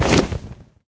wings3.ogg